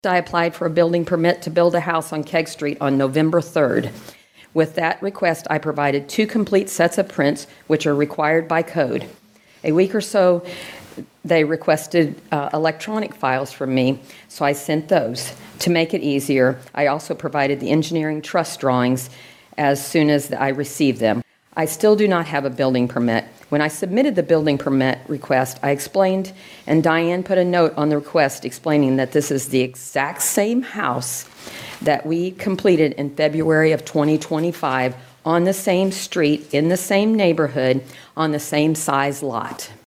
addressed the city council during their Tuesday, Dec. 2 meeting